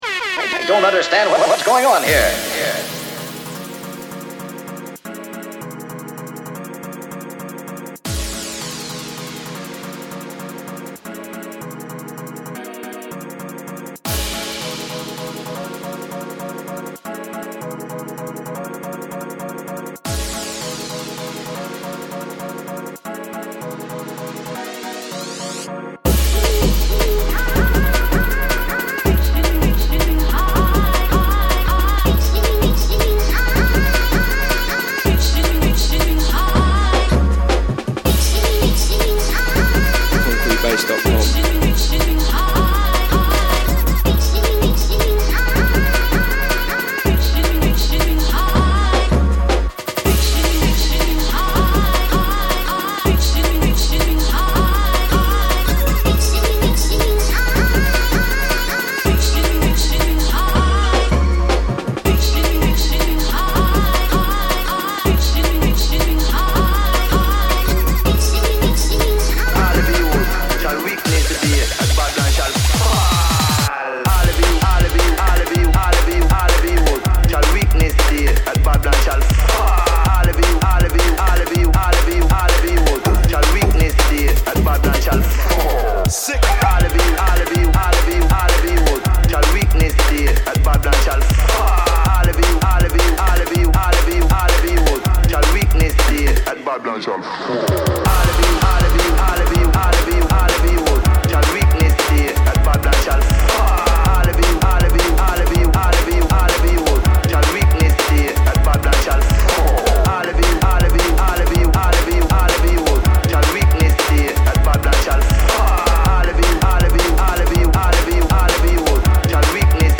juke, footwork & uk inspired bass music
exclusive mix